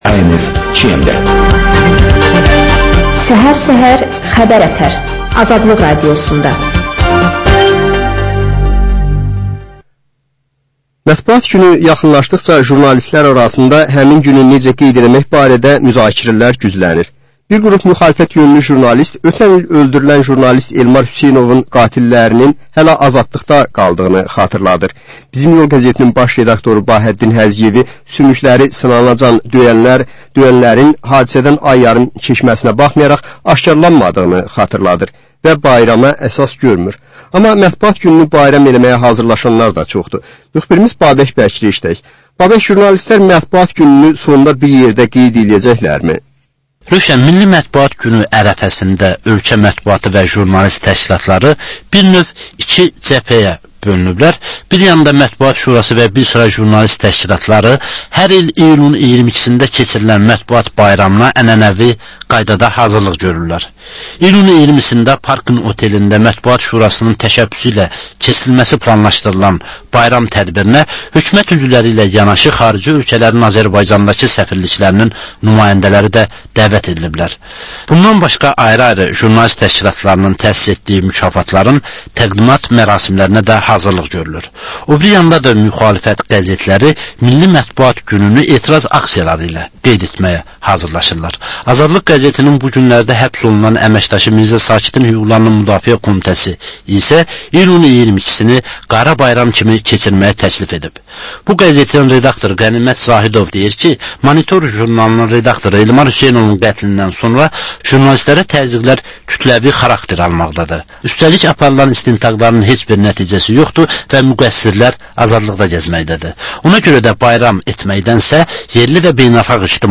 Hadisələrin təhlili, müsahibələr və xüsusi verilişlər.